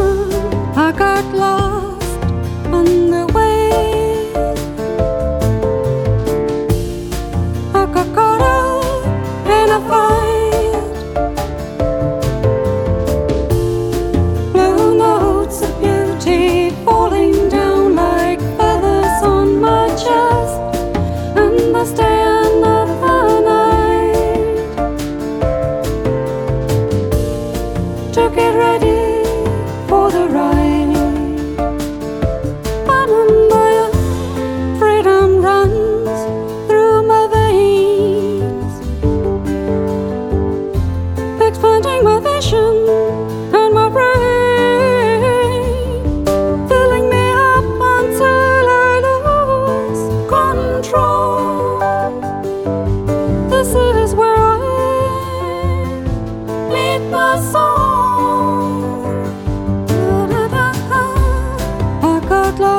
• Folk
• Pop
• Singer/songwriter
Stemningsfuld intimkoncert med vokalen i centrum